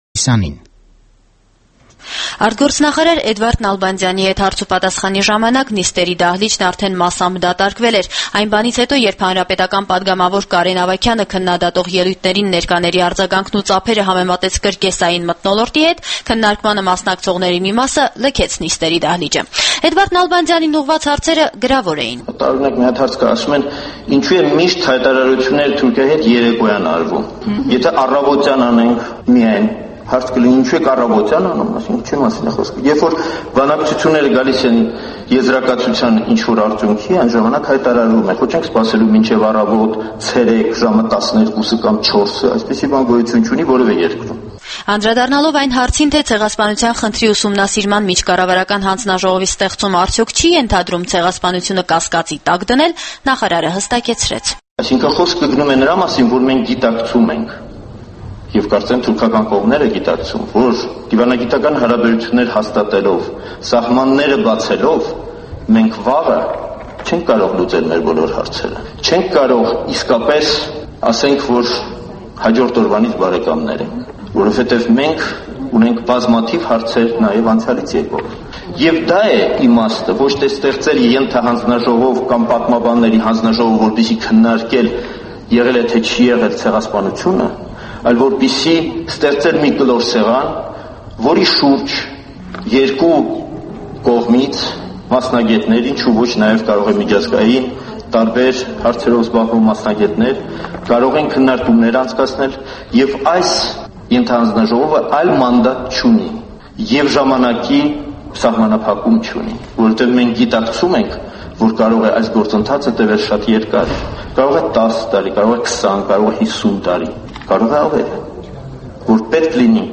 Էդվարդ Նալբանդյանը խորհրդարանում պարզաբանումներ է տալիս հայ-թուրքական կարգավորման վերաբերյալ - 2